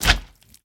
slime_big4.ogg